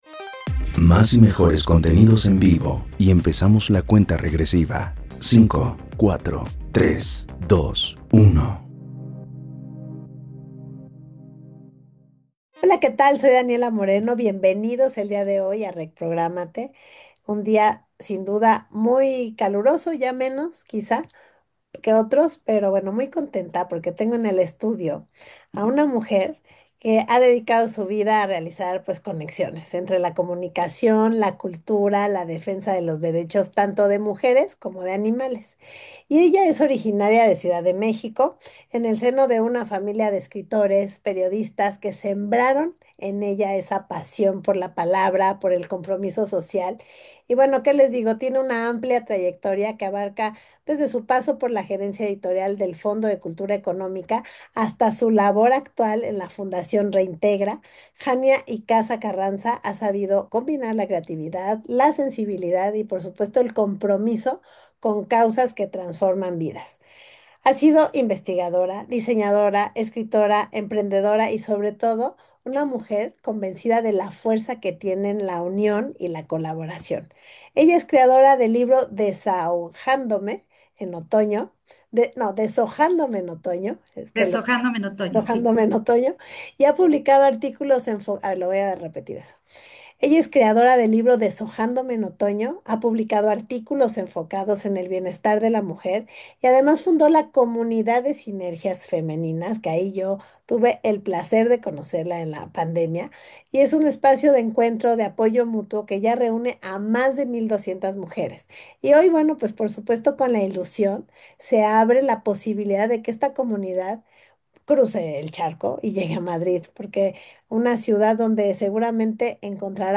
En este episodio tuve el gusto de entrevistar